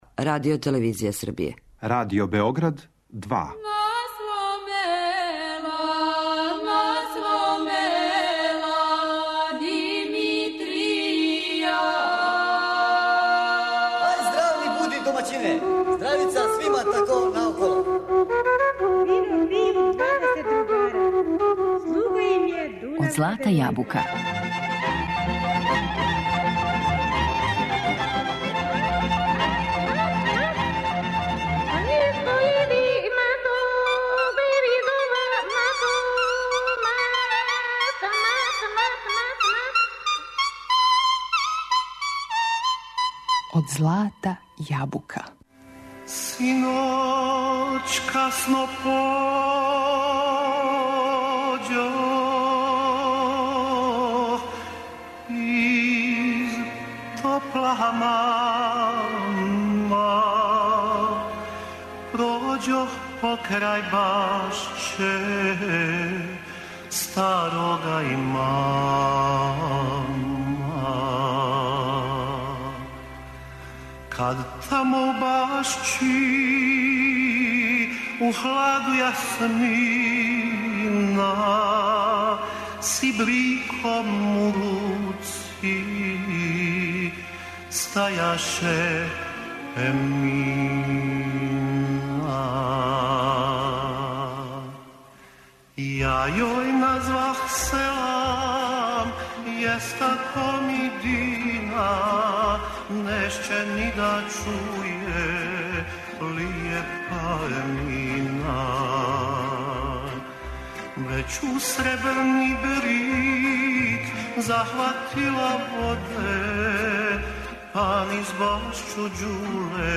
У емисији слушамо и један од последњих интервјуа који је овај уметник дао.